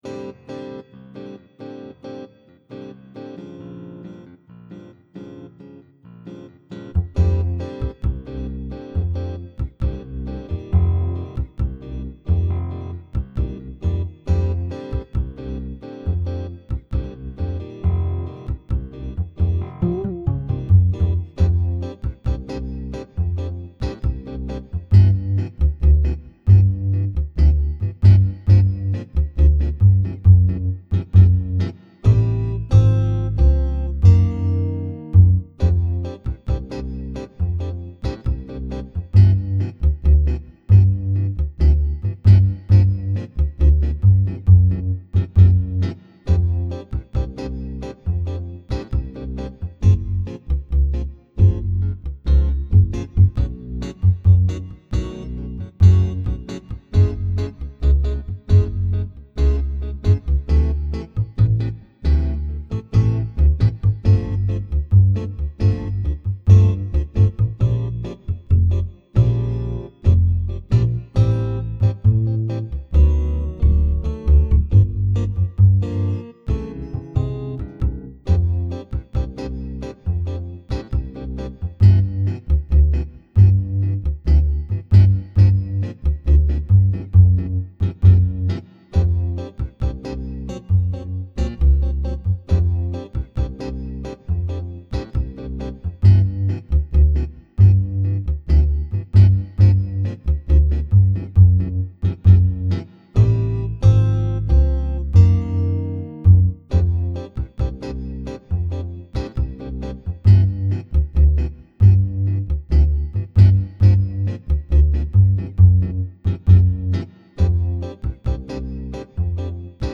Naked     (right after bass lick)